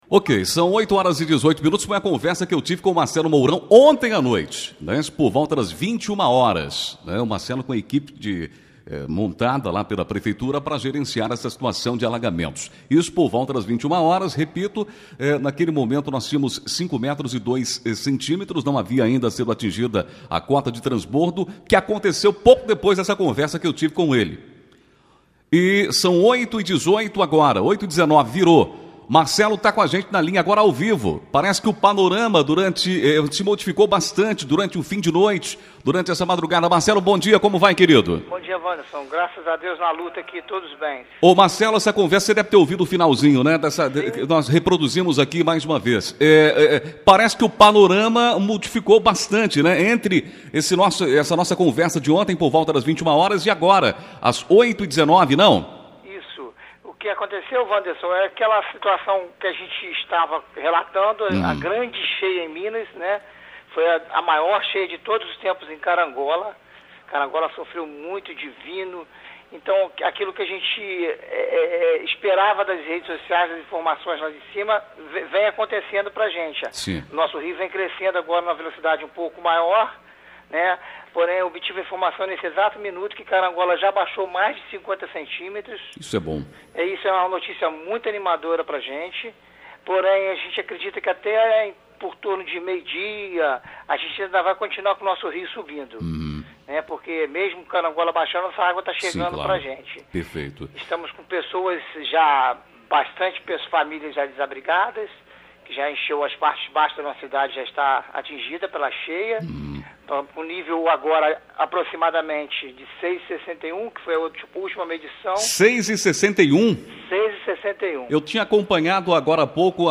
ENTREVISTA-PORCIUNCULA.mp3